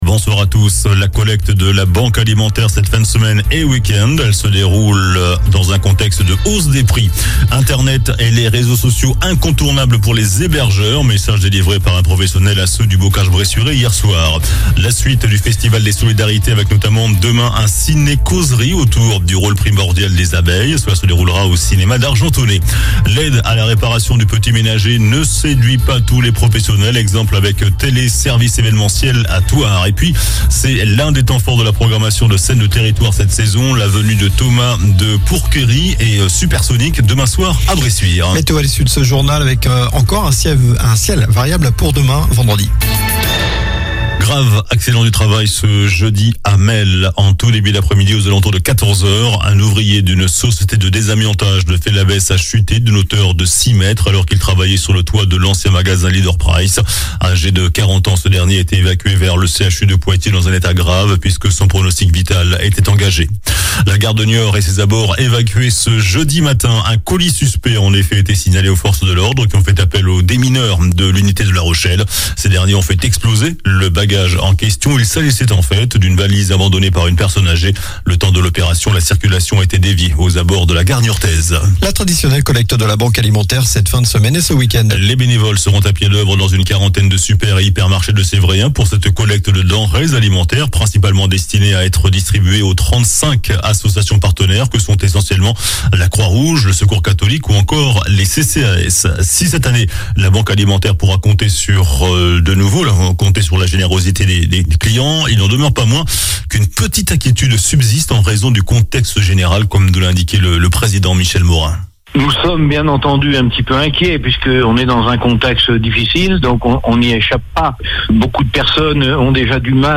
JOURNAL DU JEUDI 24 NOVEMBRE ( SOIR )